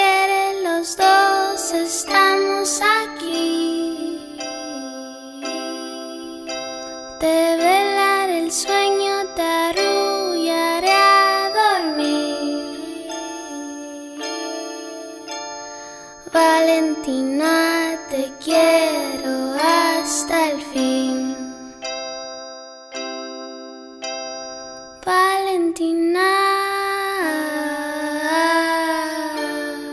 Rock y Alternativo Latin Pop Latino
Жанр: Рок / Альтернатива